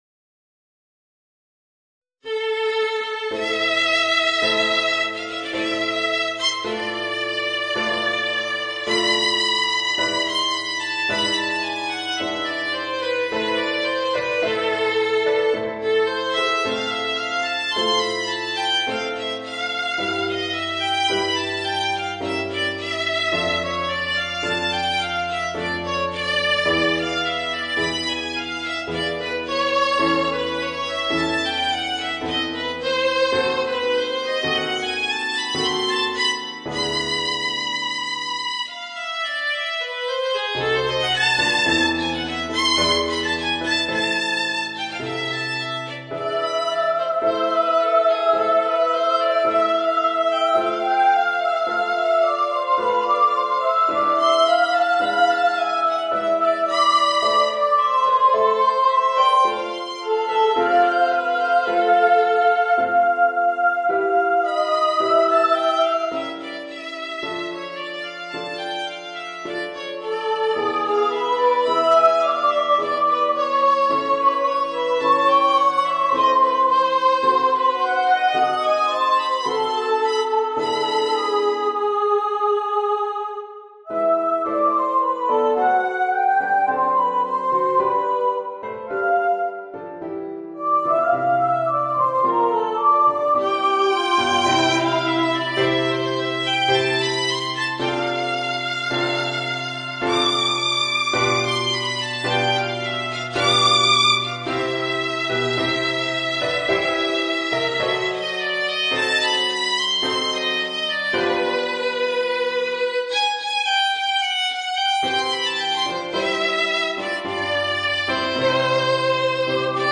Voicing: Soprano, Flute and Piano